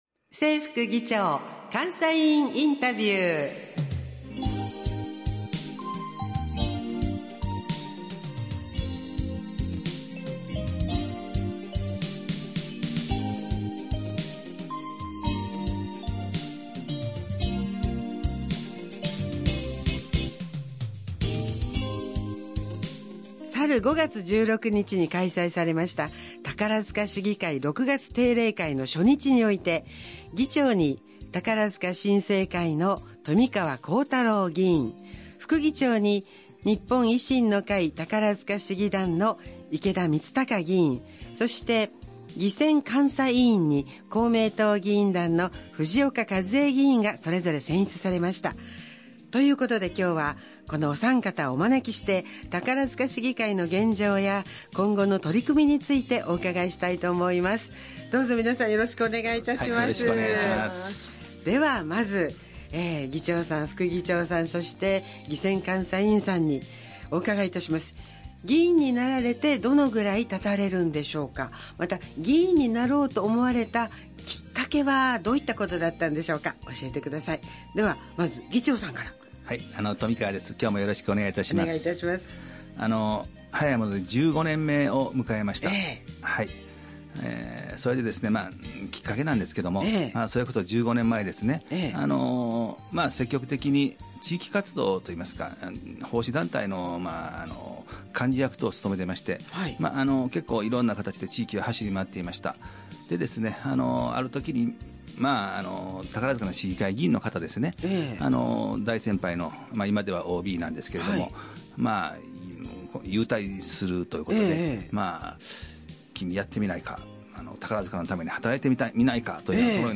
FM放送
インタビュー放送
市議会議員が直接インタビューにお答えしています。